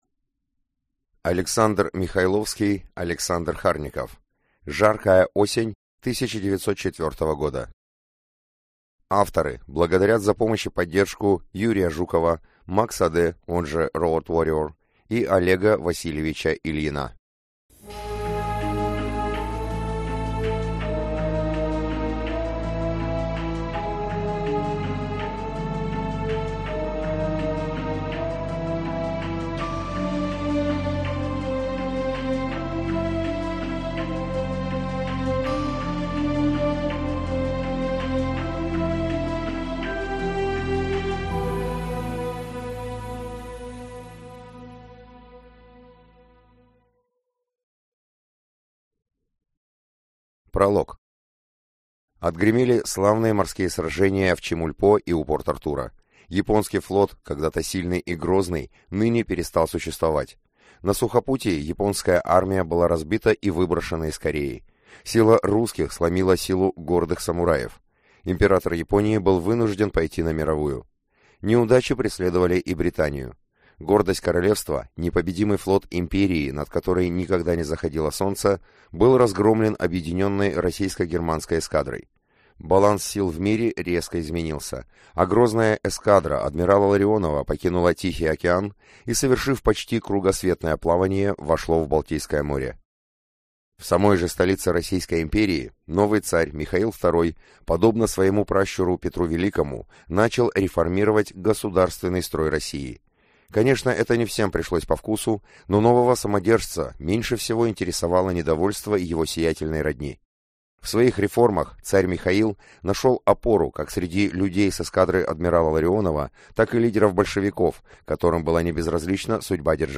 Aудиокнига Большая игра без правил